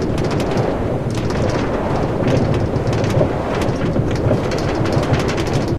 minecart / inside.ogg